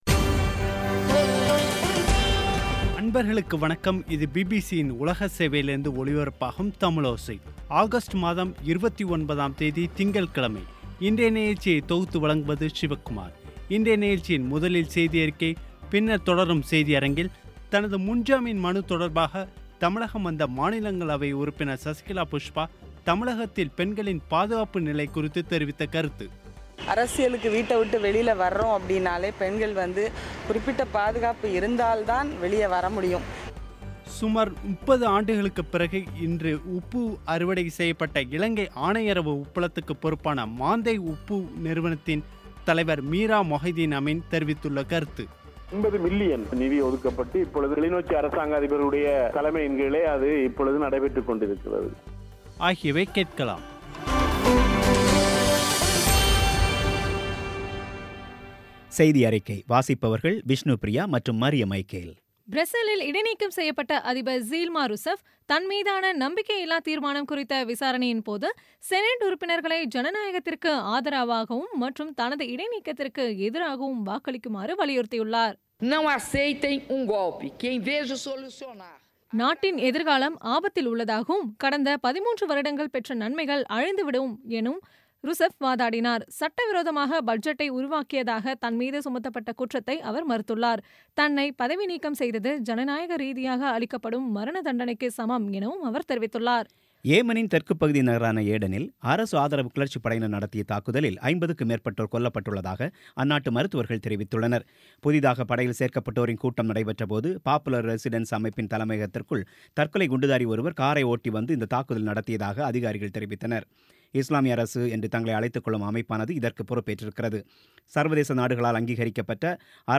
இன்றைய நிகழ்ச்சியில் முதலில் செய்தியறிக்கை, பின்னர் தொடரும் செய்தியரங்கில்